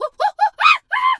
monkey_2_chatter_scream_02.wav